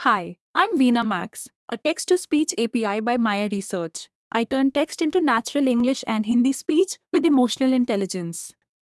bilingual bilingual-tts hindi hindi-english hindi-tts text-to-speech
VeenaMAX is a high-performance, advanced Text-to-Speech API that converts text into natural-sounding speech with emotional intelligence and blazing-fast response times.
"speaker": "vinaya_assist",